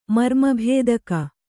♪ marma bhēdaka